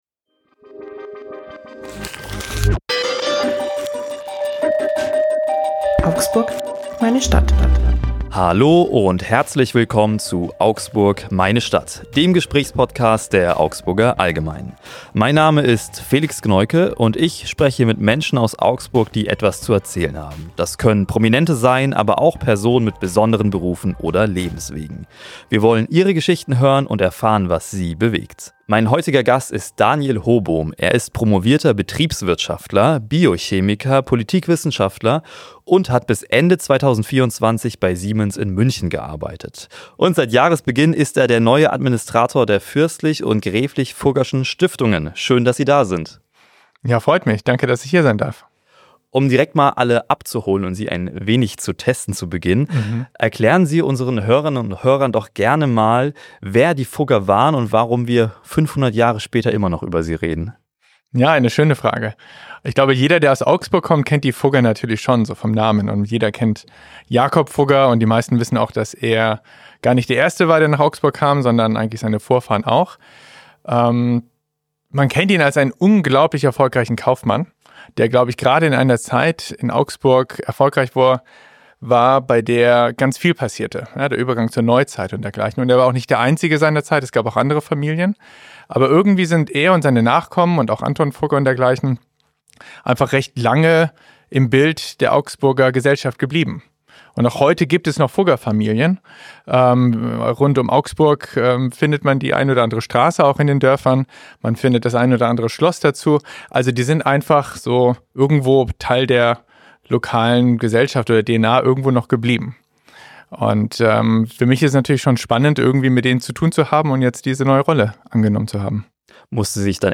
Im Interview-Podcast „Augsburg, meine Stadt